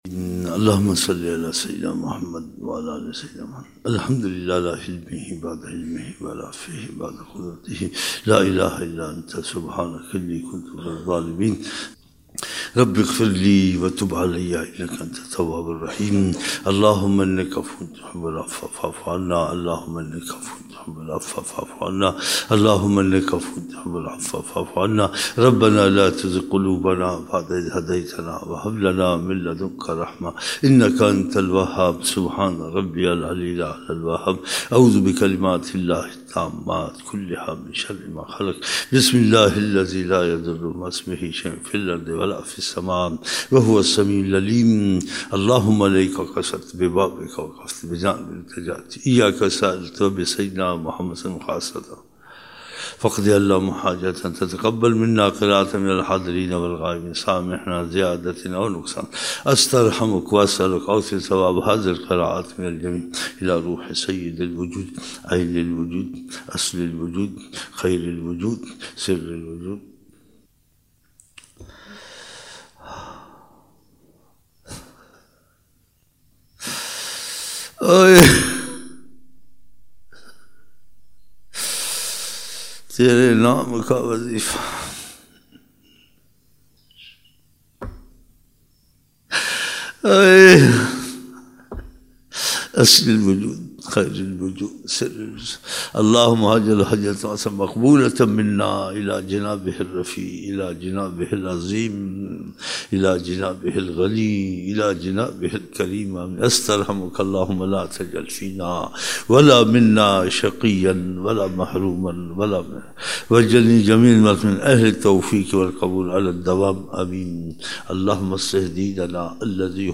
17 November 1999 - Fajar Dua (9 Shaban 1420)